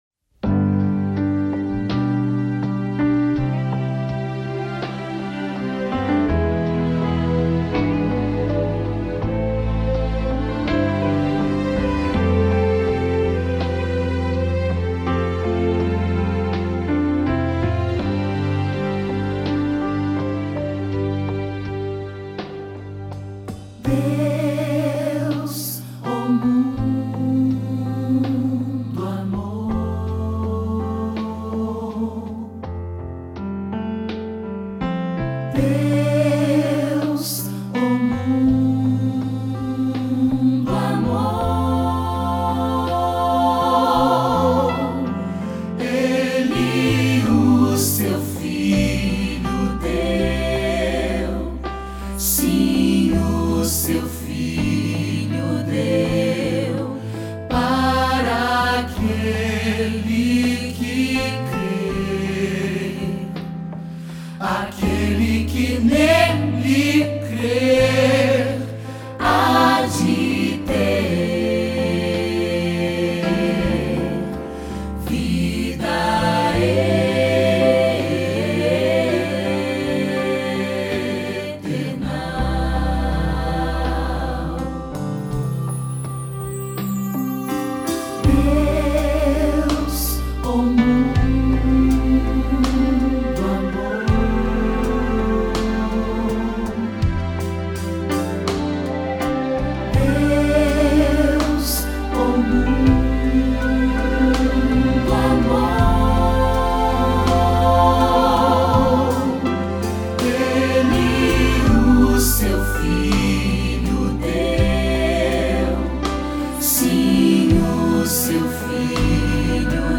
cantata